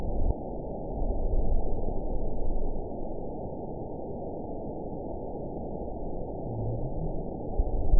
event 920129 date 02/24/24 time 21:26:07 GMT (1 year, 2 months ago) score 8.18 location TSS-AB01 detected by nrw target species NRW annotations +NRW Spectrogram: Frequency (kHz) vs. Time (s) audio not available .wav